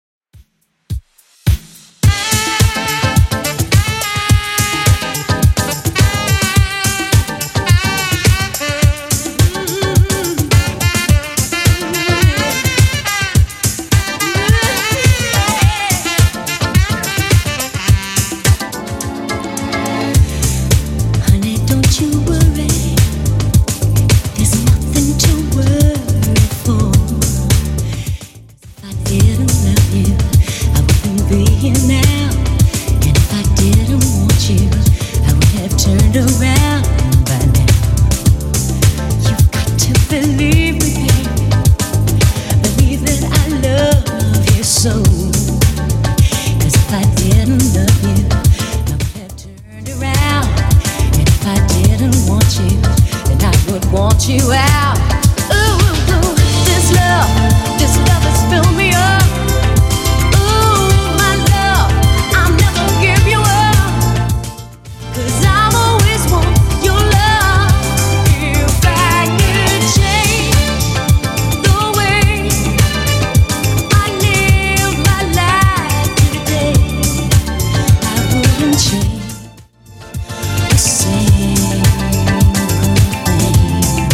BPM: 106 Time